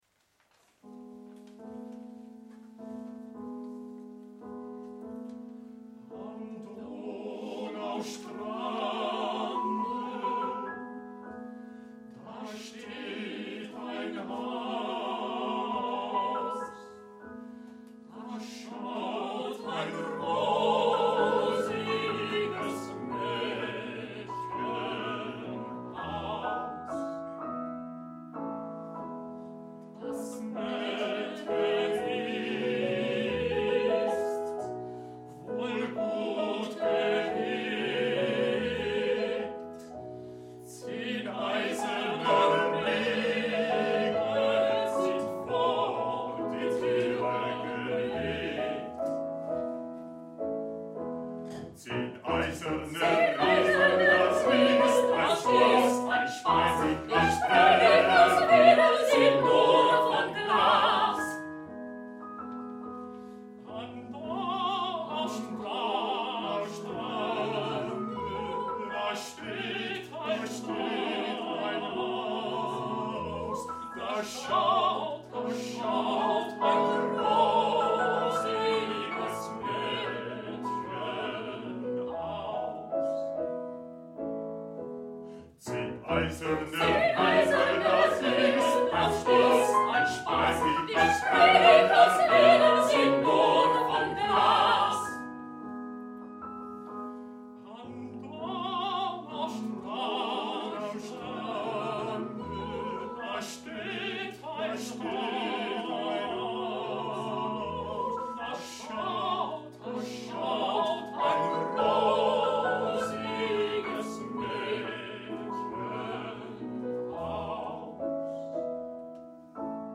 BEGELEIDING LIED EN OPERA/ OPERETTE ARIA’S